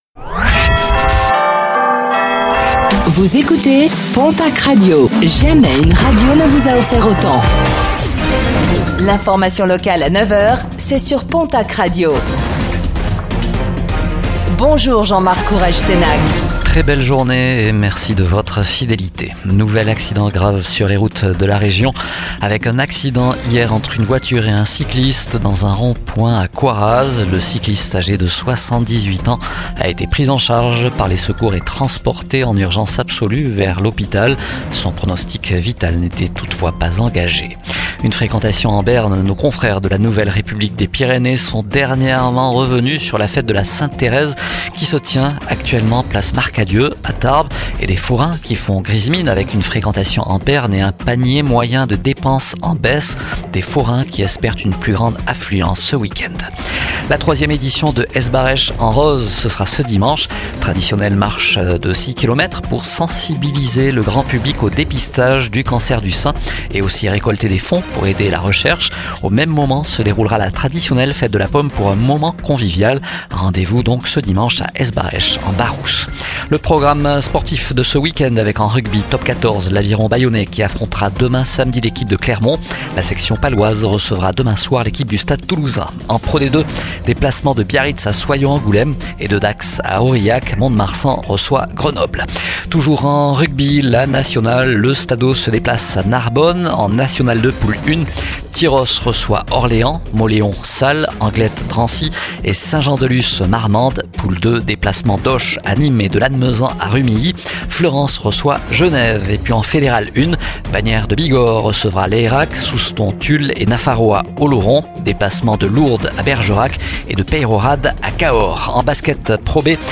Réécoutez le flash d'information locale de ce vendredi 17 octobre 2025